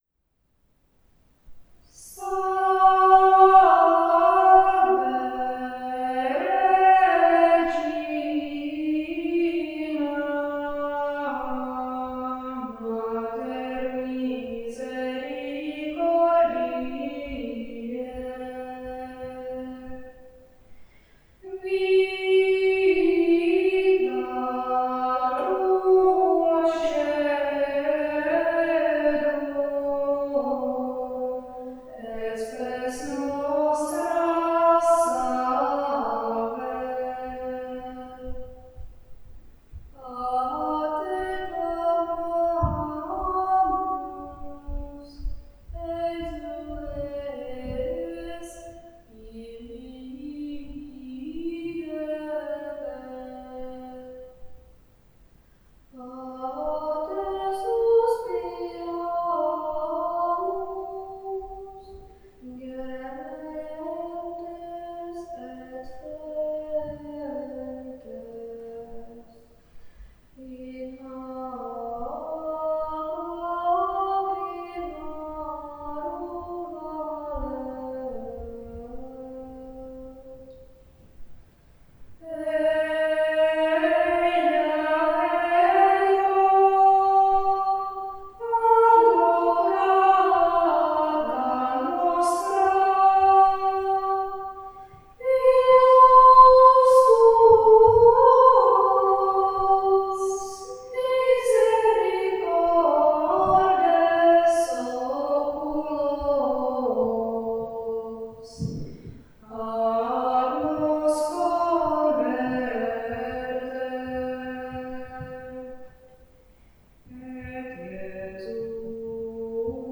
Státní hrad a zámek Bečov nad Teplou Ploužnice hrad kaple malby studentská tvorba